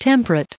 800_temperate.ogg